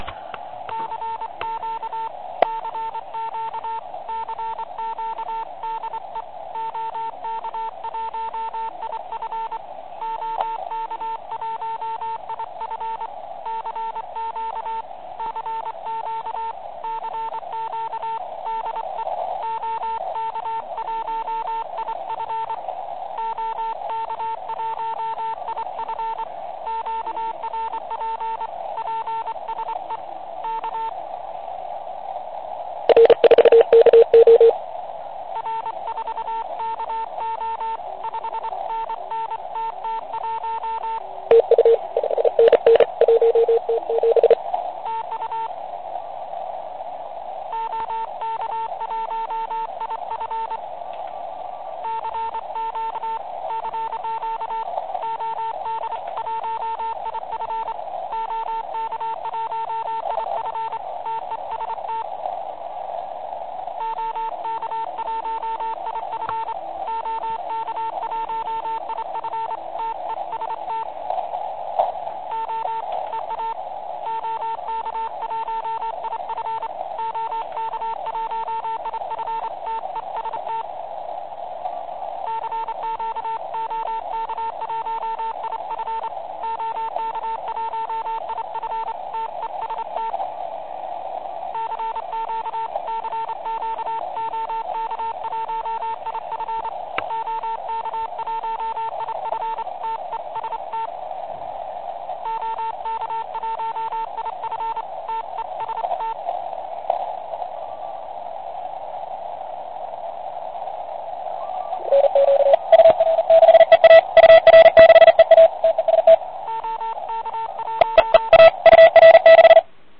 Jen tak malinko jsem to protáhl bandem: